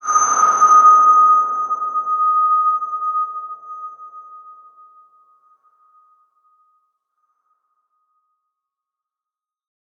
X_BasicBells-D#4-mf.wav